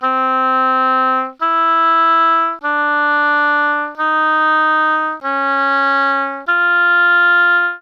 oboe.aiff